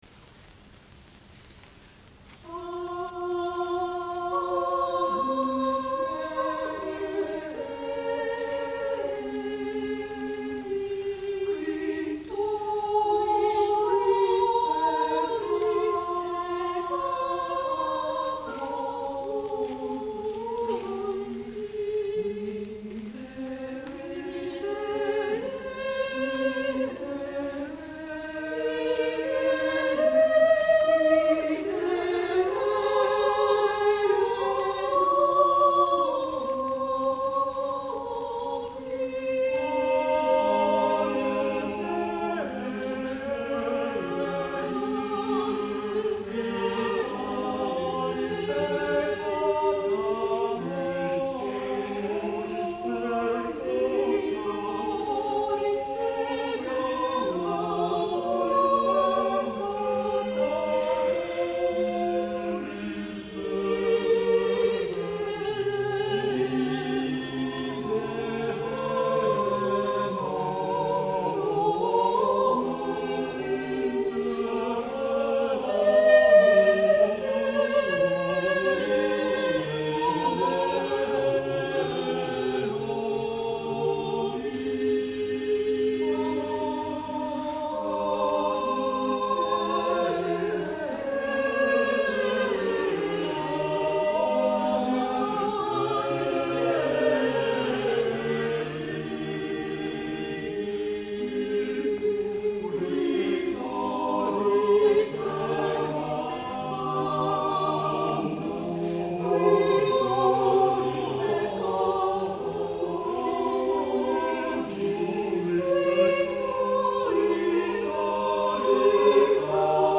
第35回野田市合唱祭
野田市文化会館
Mass for four voices より　William Byrd　バード